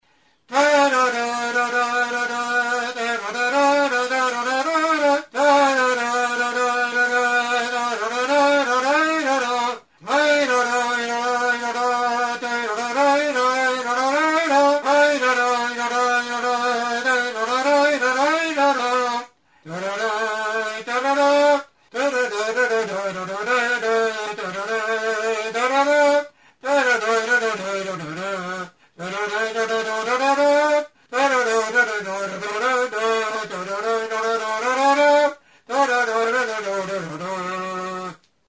Membranophones -> Mirliton
Recorded with this music instrument.
EUROPE -> EUSKAL HERRIA
Plastikozko orrazi txuria da.